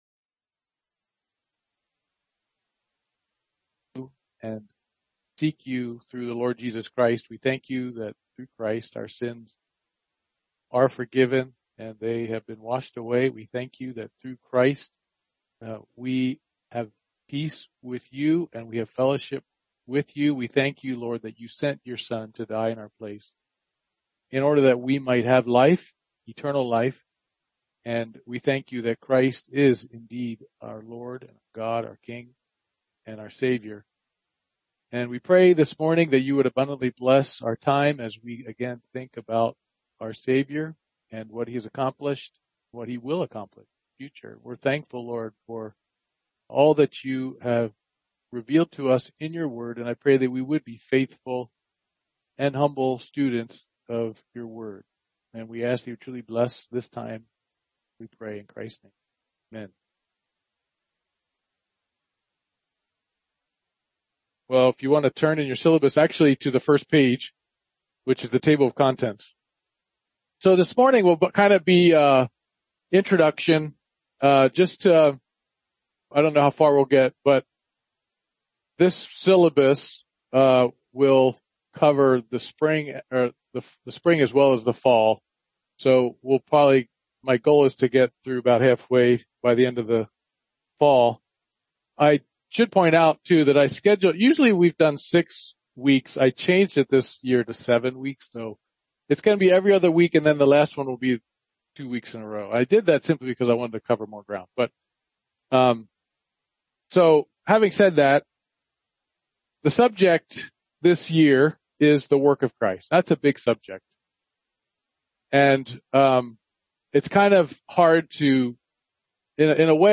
Men's Bible Study